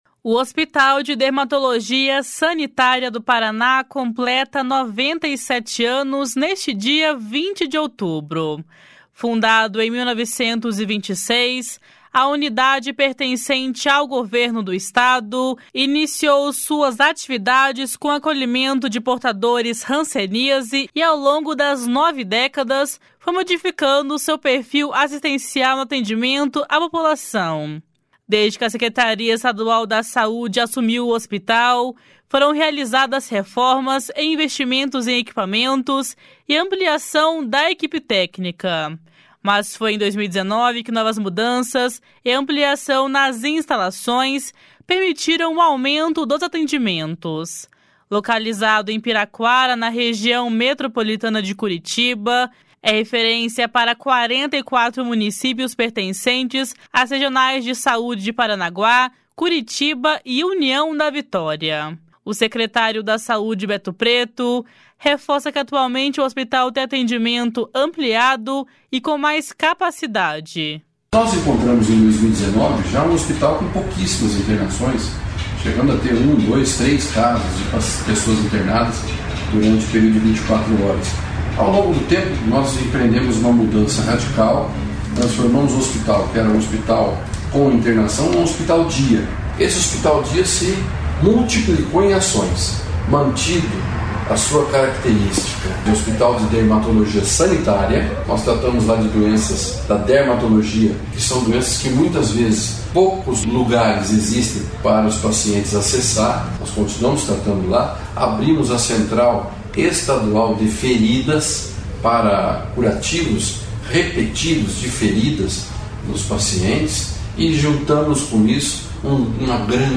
O secretário da Saúde, Beto Preto, reforça que atualmente o hospital tem atendimento ampliado e com mais capacidade. // SONORA BETO PRETO //